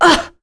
Priscilla-Vox_Damage_02.wav